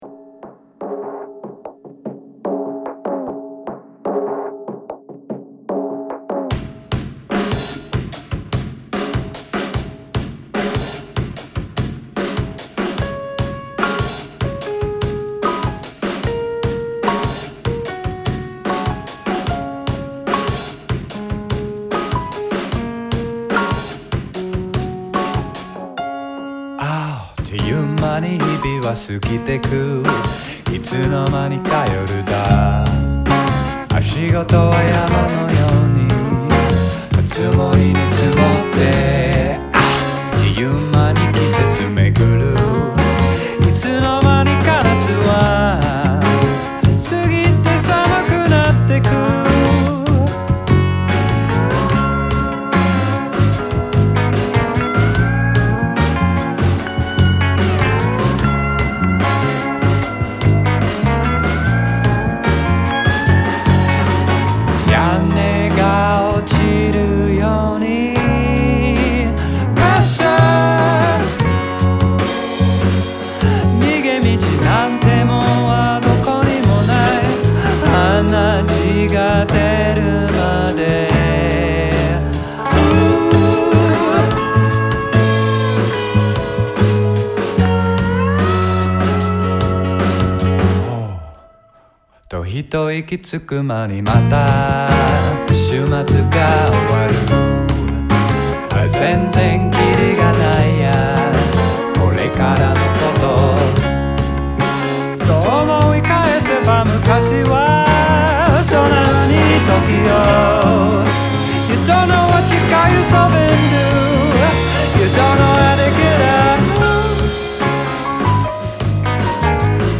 R&B/ Hip-Hop